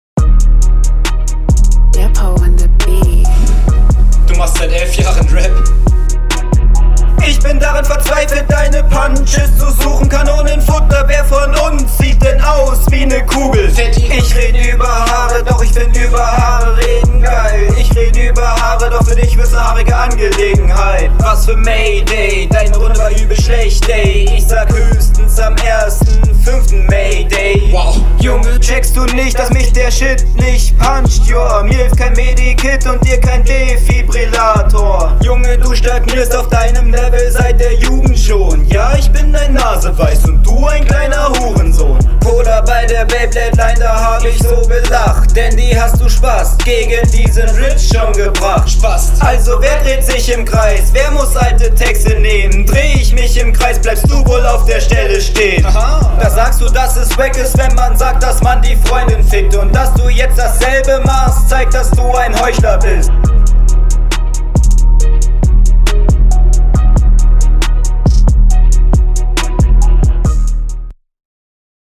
Flow und Stimmeinsatz wie bei deinem Gegner eher monoton, allerdings ist die Delivery viel besser …
Mag ich etwas mehr, klingt aber auch noch recht unroutiniert.
Soundtechnisch könnte das ganze direkter klingen.